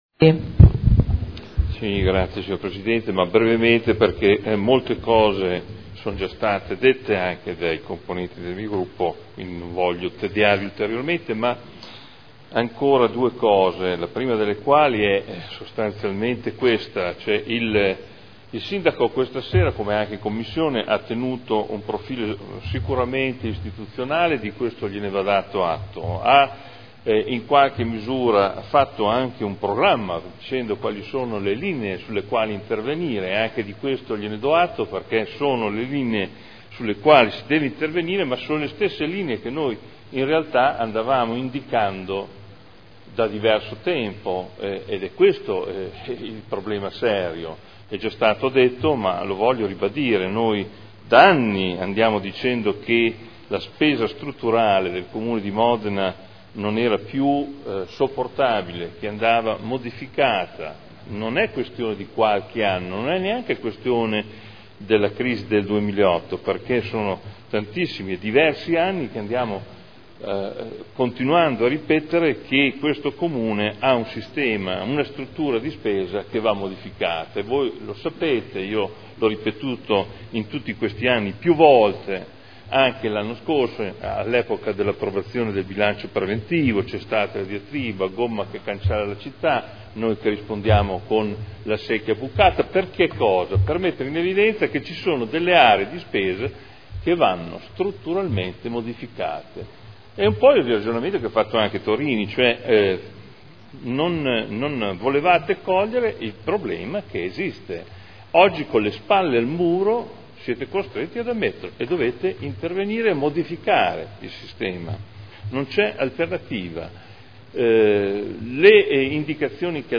Seduta del 26 settembre 2011 Ricadute della manovra del Governo sul Bilancio del Comune di Modena – Dibattito